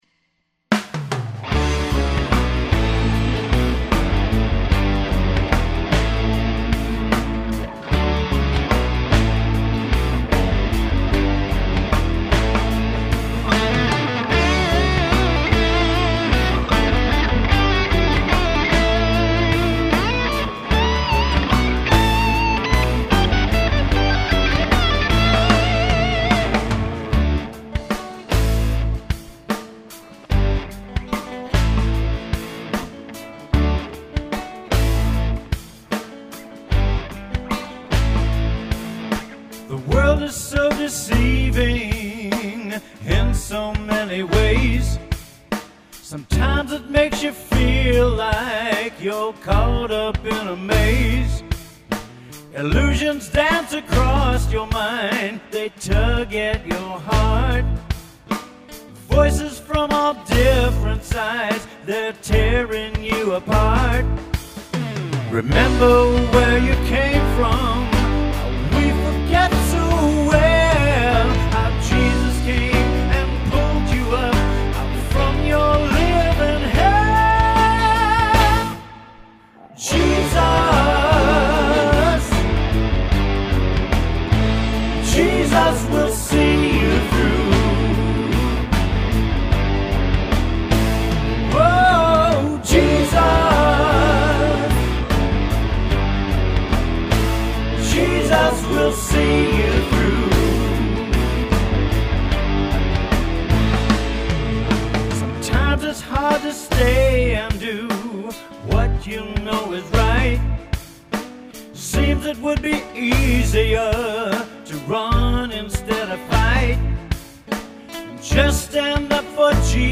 Me lead vocals
Me on Lead